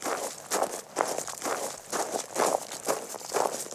yell-sounds-walking.ogg